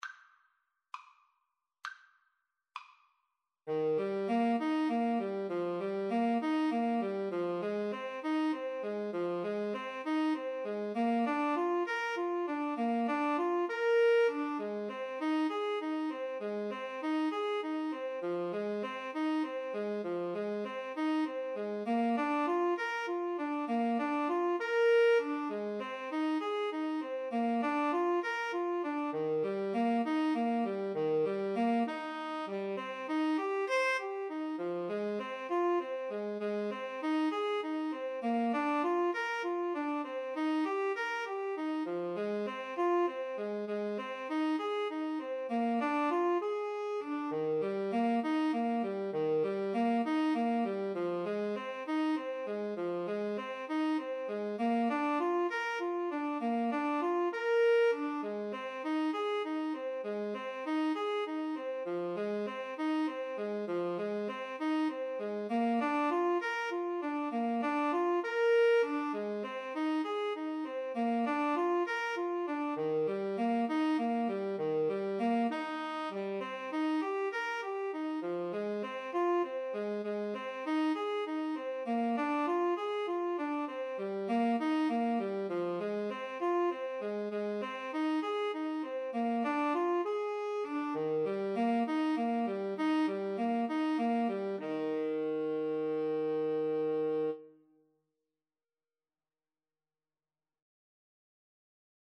FluteTenor Saxophone
6/8 (View more 6/8 Music)